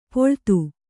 ♪ poḷtu